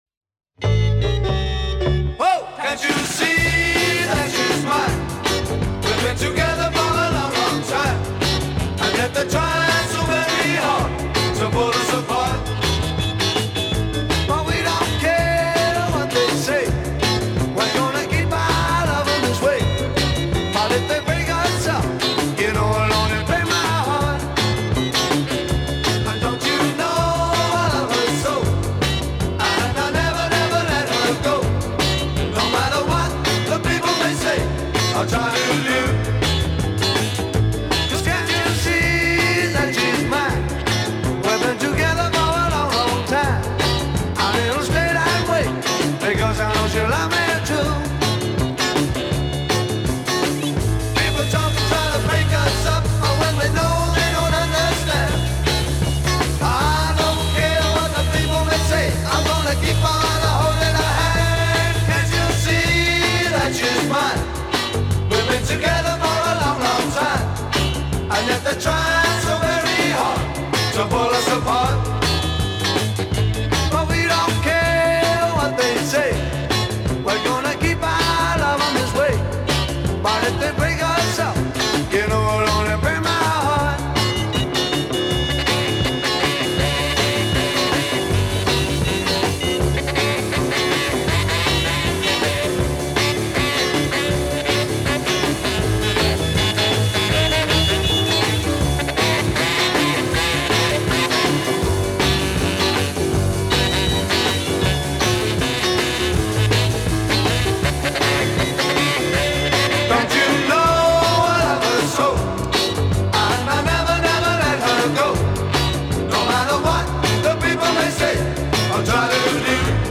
бит
поп-рок